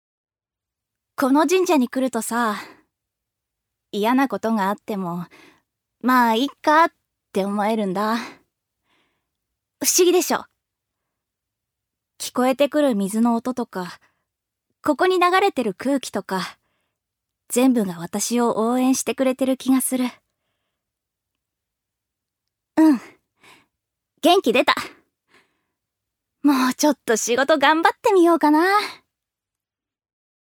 預かり：女性
セリフ４